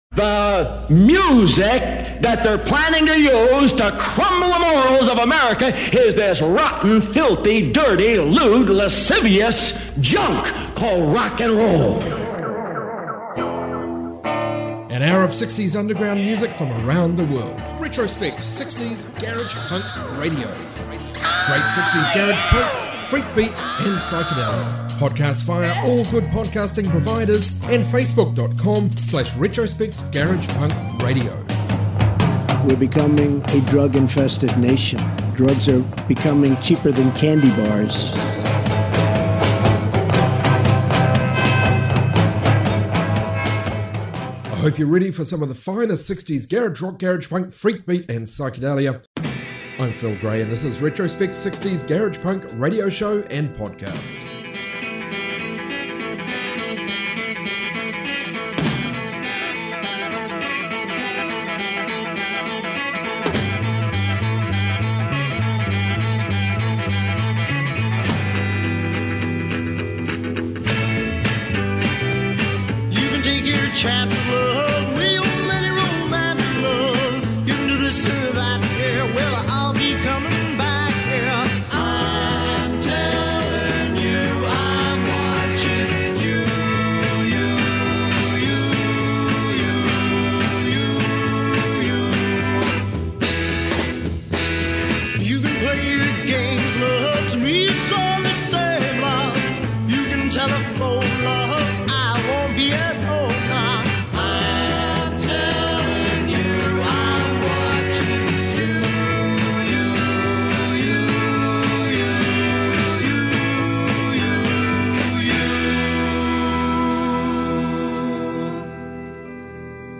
60s garage rock garage punk freakbeat